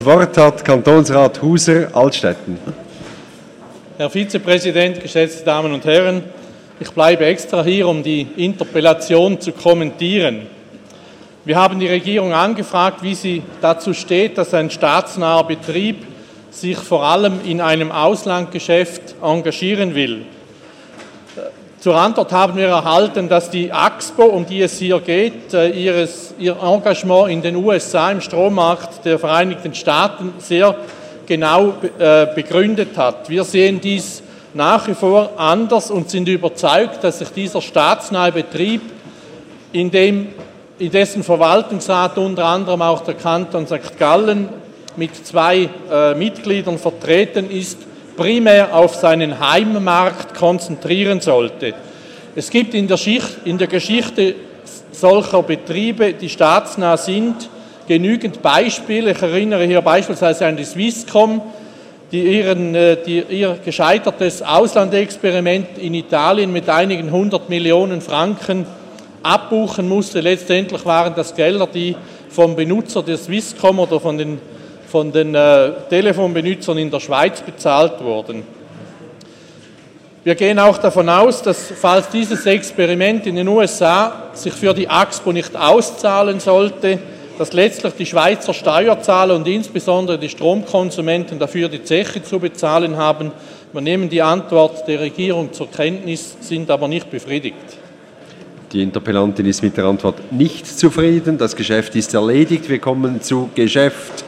1.12.2015Wortmeldung
Session des Kantonsrates vom 30. November bis 2. Dezember 2015